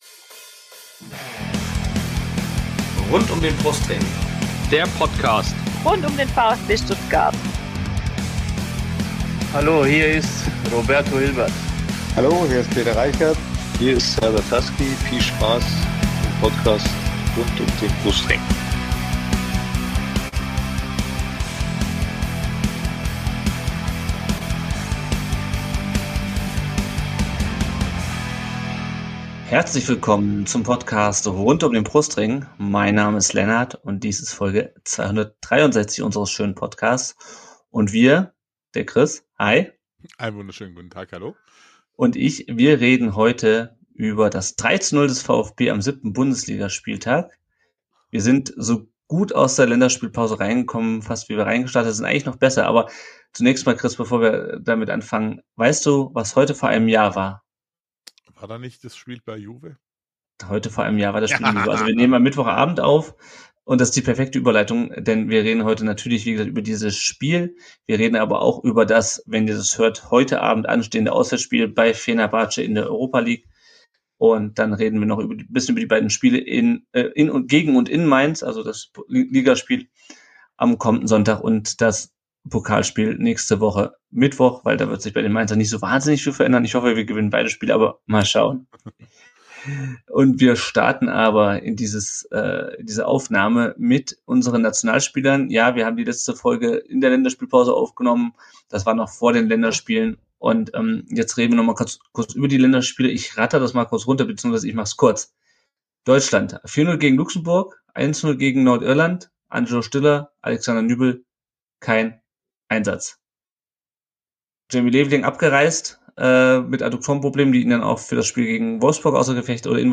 Auch diese Folge konntet Ihr live auf Twitch mitverfolgen!